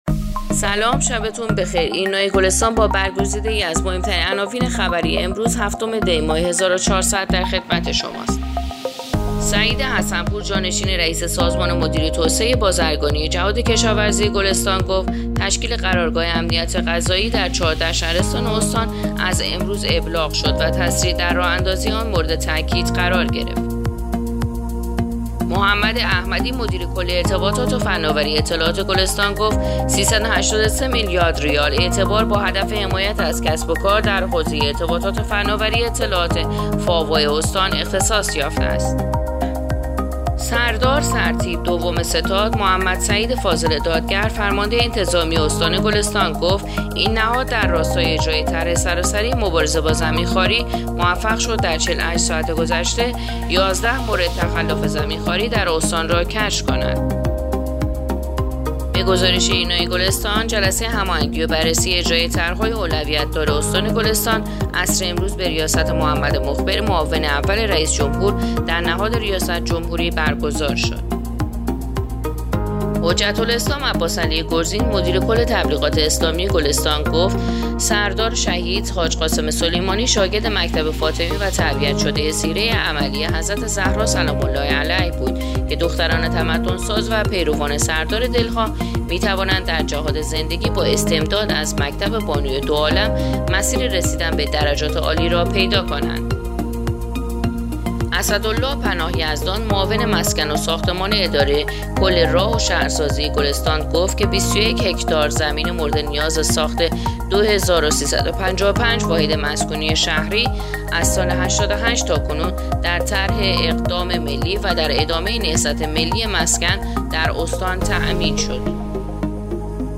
پادکست/ اخبار شامگاهی هفتم دی ماه ایرنا گلستان